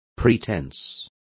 Complete with pronunciation of the translation of pretence.